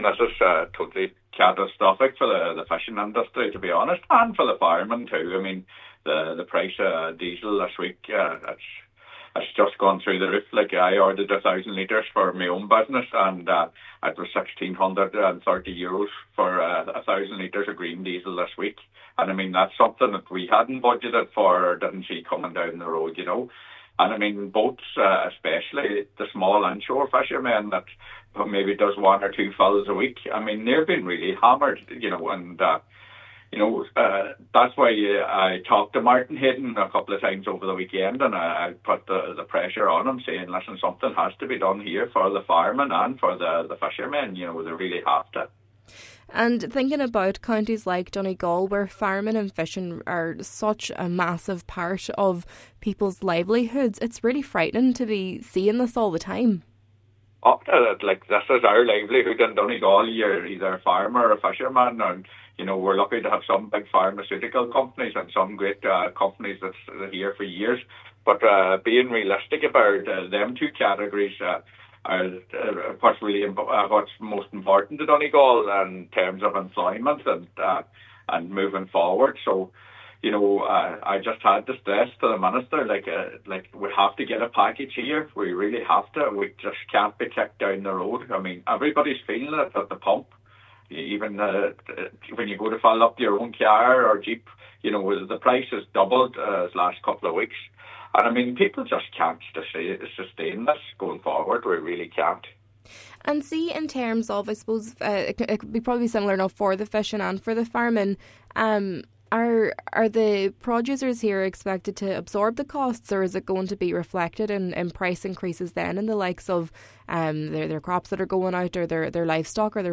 Senator Boyle says a satisfactory outcome in the coming days is crucial to keep these vital sectors operational: